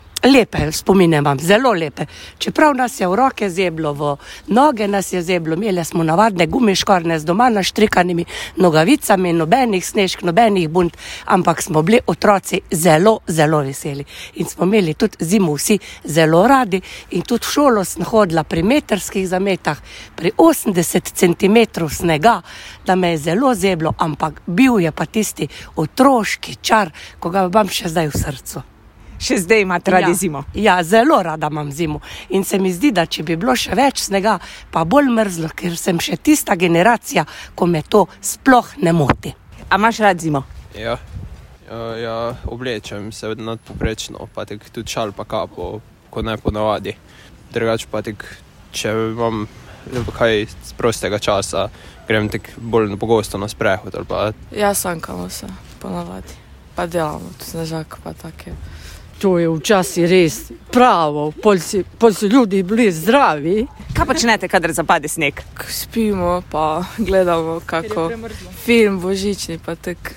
Z mikrofonom med vami
Nekaj naključnih mimoidočih pa o zimi in mrazu takole: